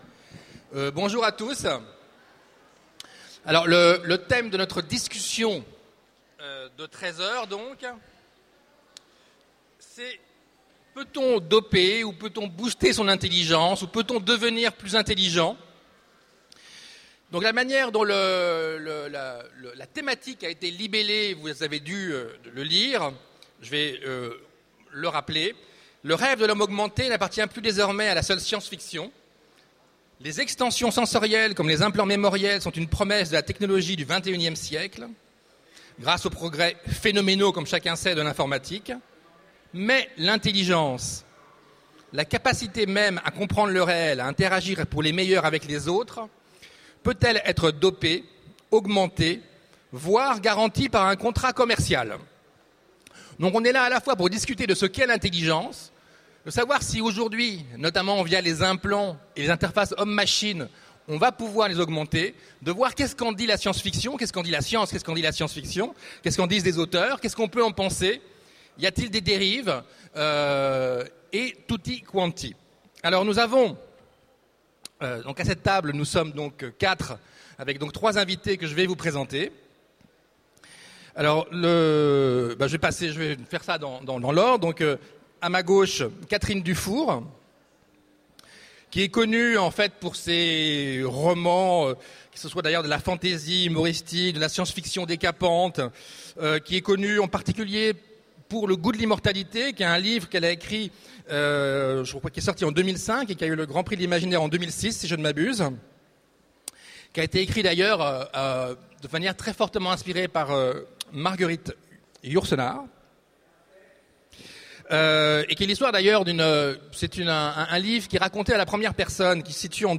Mots-clés Surhomme Conférence Partager cet article